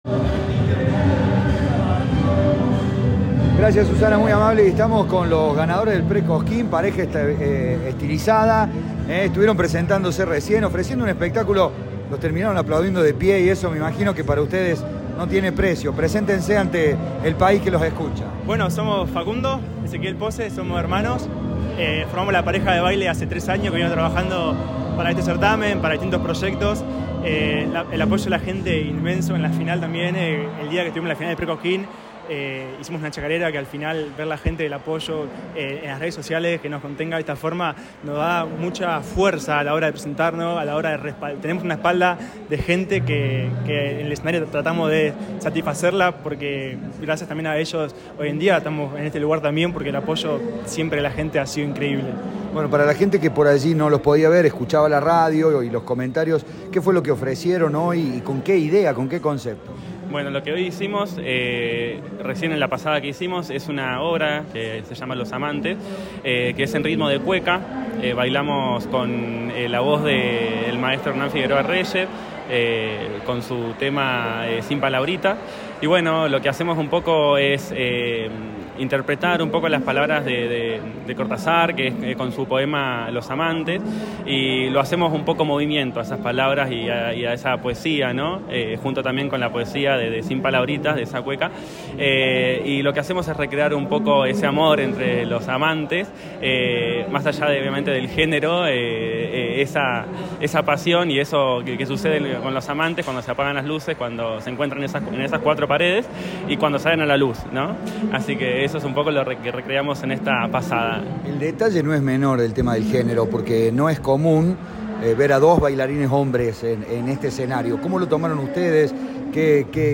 En diálogo con Cadena 3, aseguraron que "el arte folclórico no tiene género".